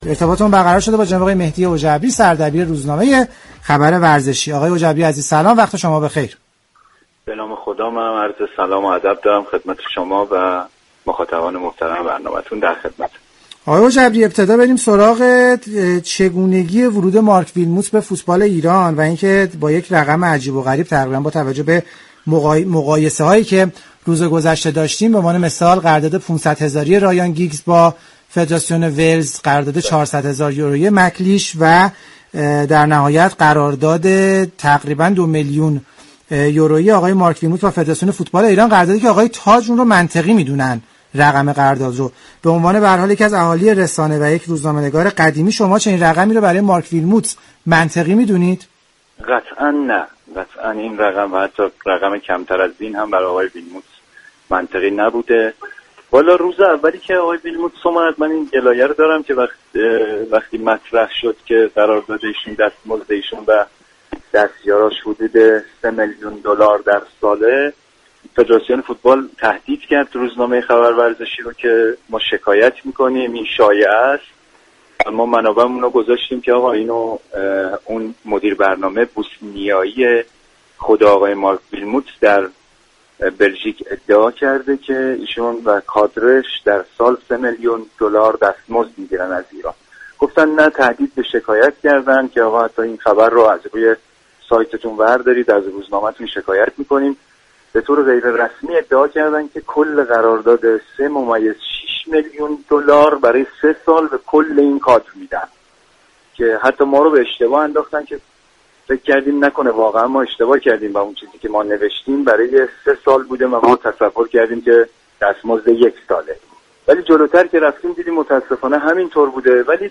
برنامه زنده "از فوتبال چه خبر؟"